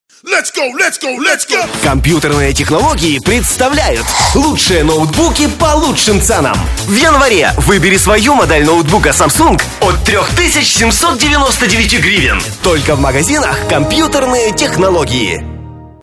Радио-ролик для сети магазинов "Компьютерные технологии" Категория: Аудио/видео монтаж